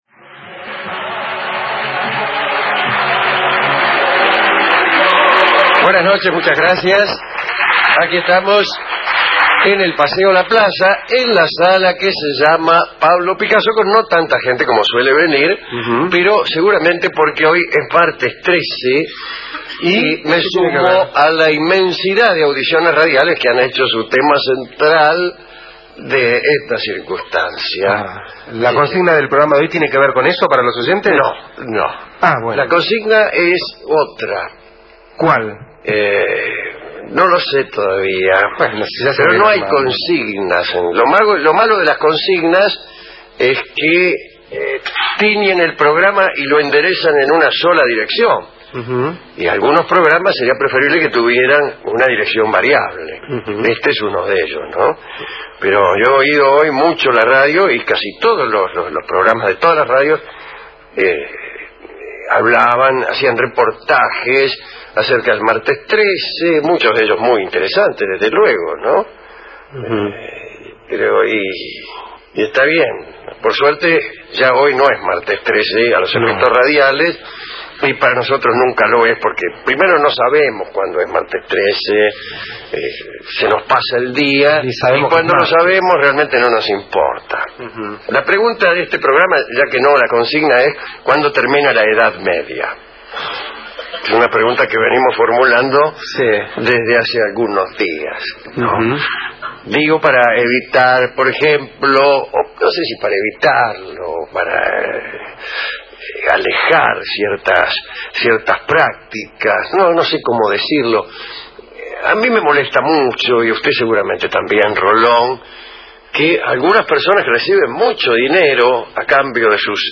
Paseo La Plaza, Sala Pablo Picasso, Buenos Aires Alejandro Dolina, Gabriel Rolón, Gillespi Segmento Inicial Martes 13 Una manera de no pagar nunca en un restaurante No estamos solari Segmento